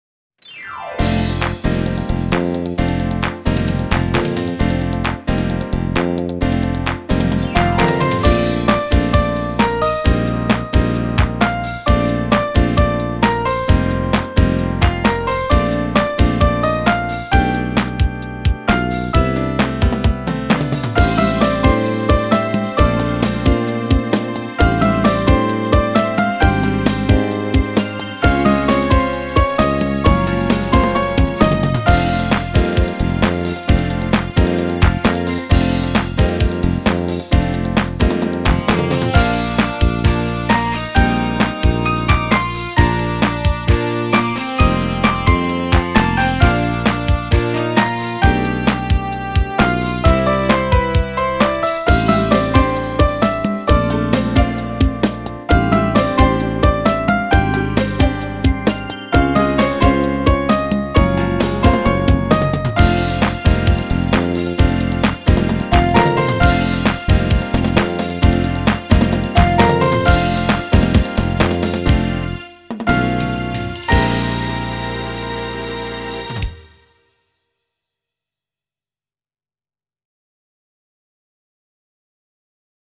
Flute and Piano with optional backing tracks
More jazz titles